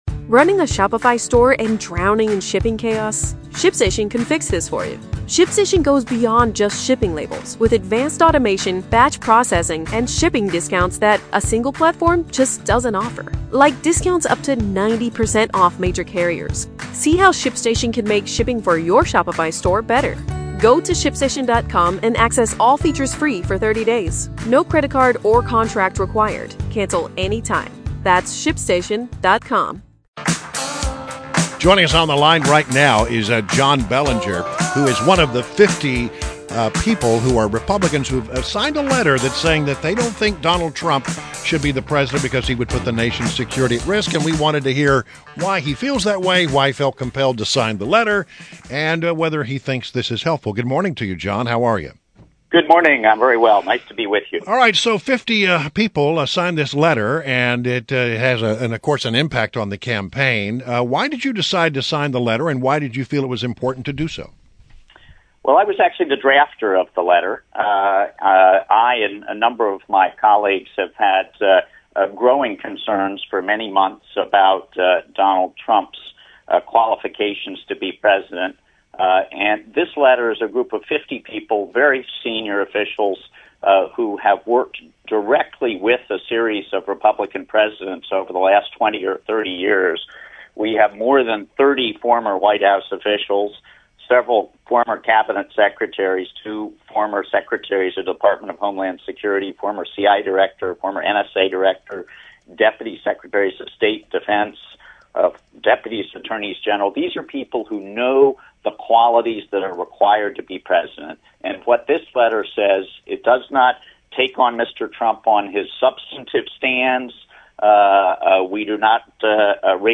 WMAL Interview - JOHN BELLINGER - 08.10.16